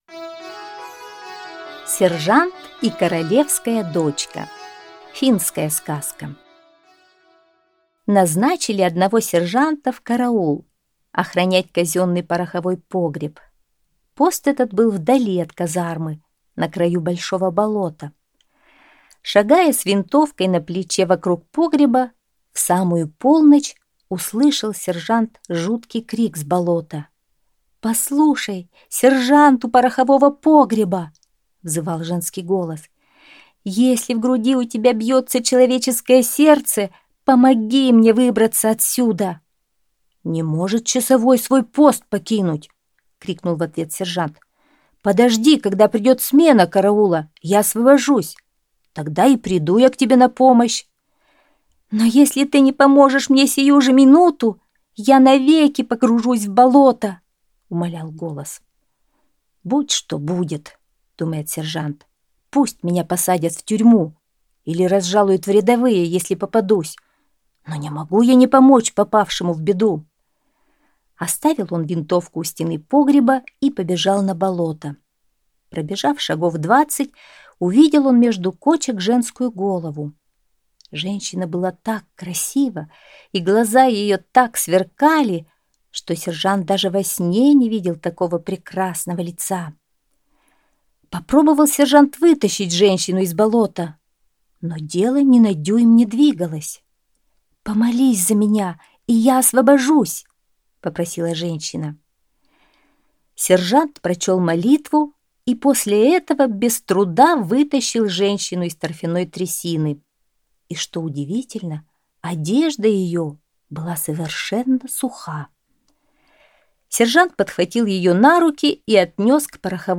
Сержант и королевская дочка - финская аудиосказка - слушать